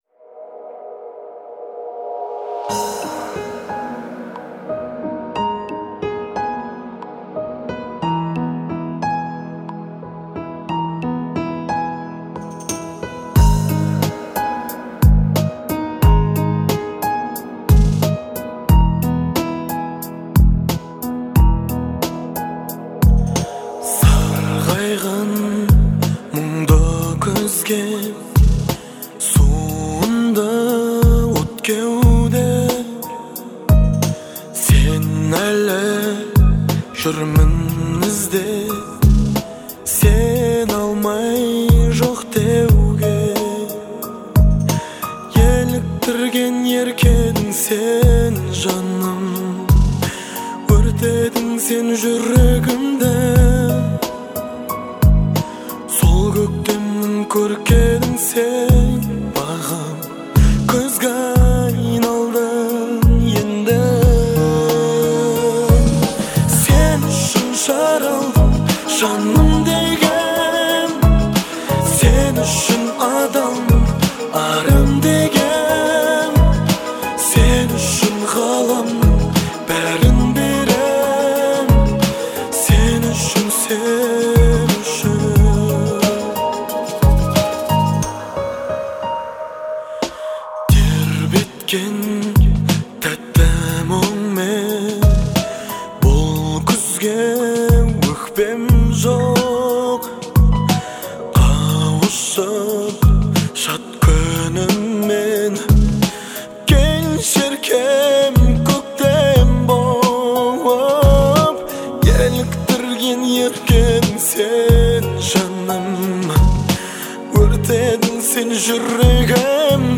это трек в жанре поп с элементами казахской музыки